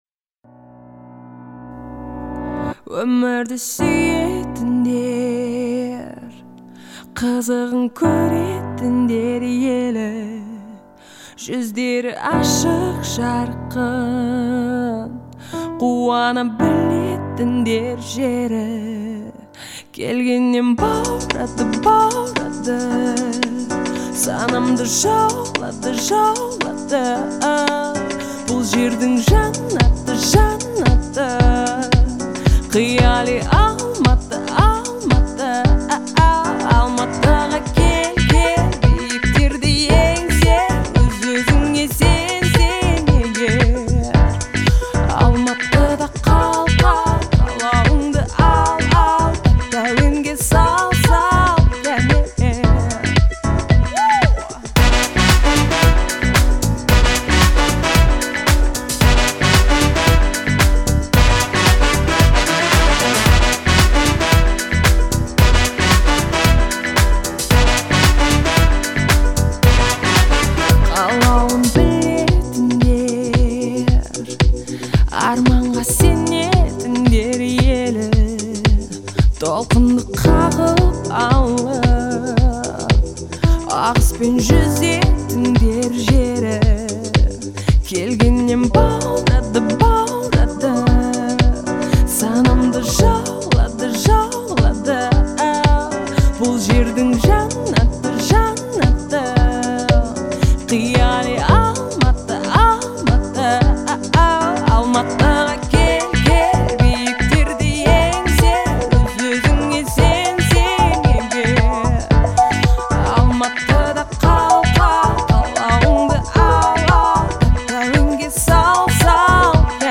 это яркая и мелодичная песня
эмоциональная подача и чистота голоса